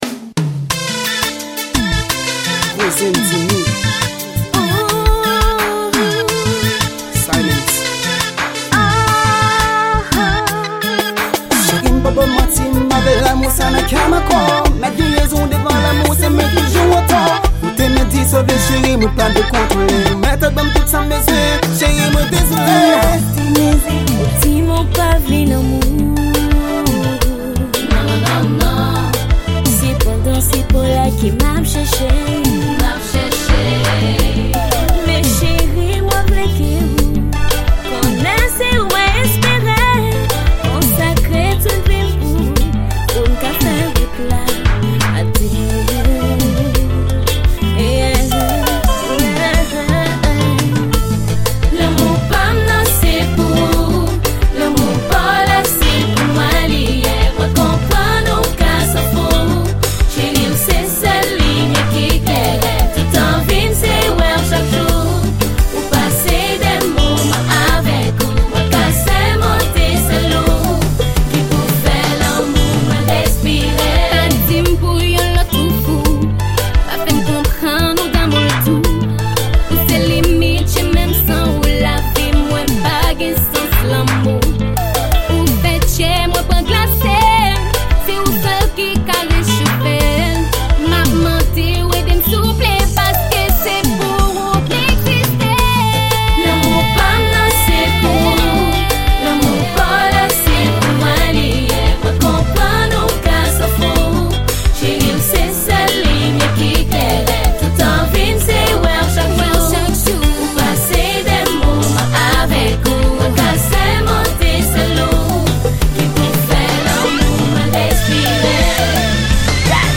Genre: COMPAS.